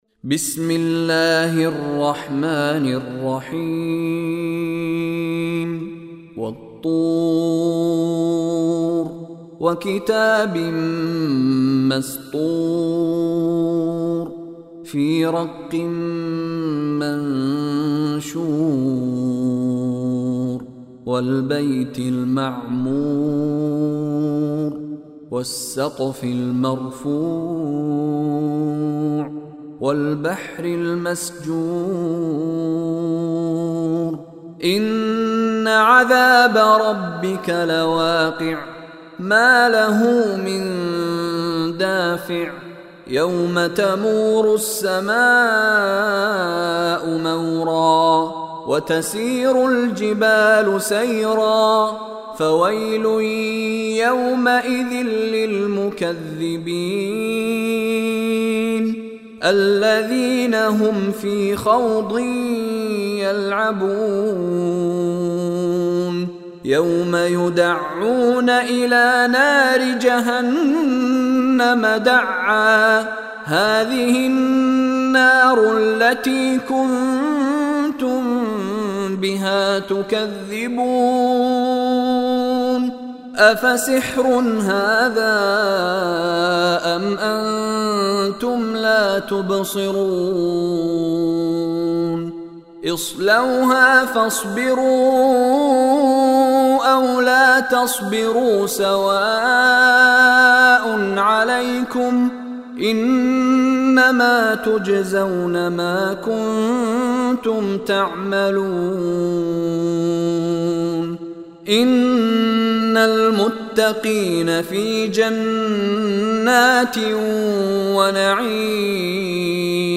Surah At Tur Online Recitation by Mishary Rashid
This surah is recited in Arabic by Sheikh Mishary Rashid Alafasy.